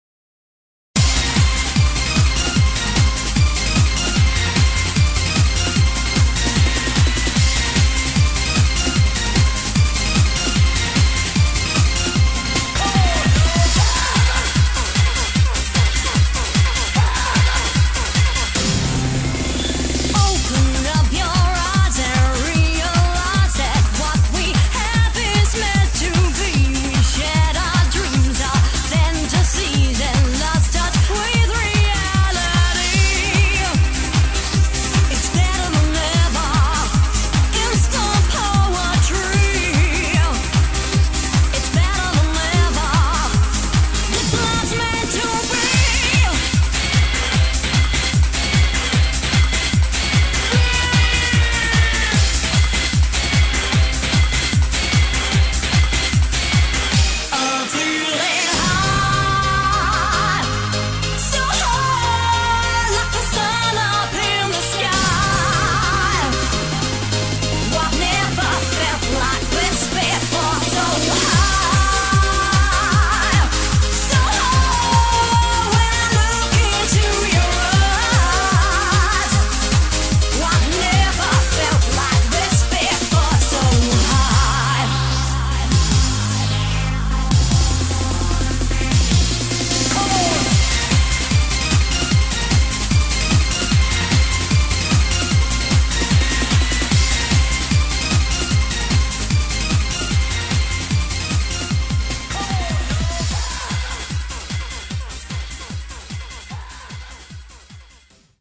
BPM150
Audio QualityMusic Cut